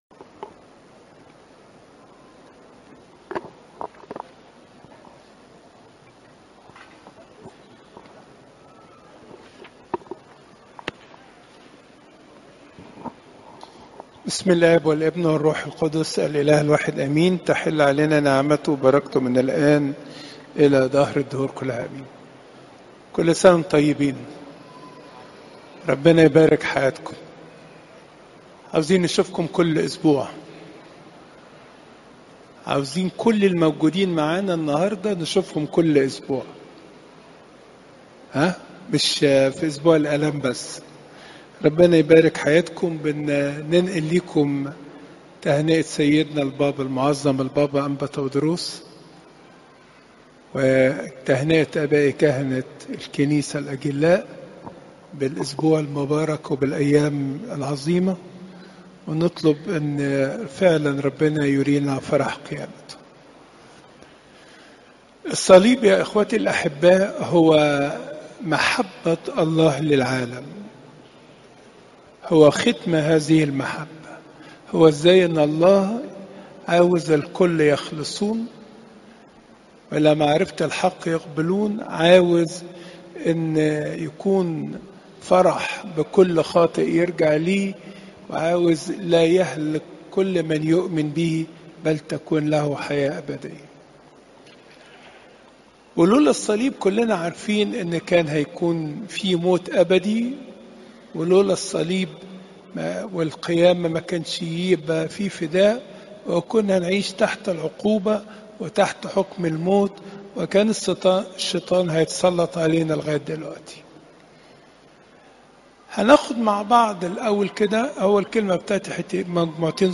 عظات الكنيسة - بطريركية الاقباط الارثوذكس - كنيسة السيدة العذراء مريم بالزيتون - الموقع الرسمي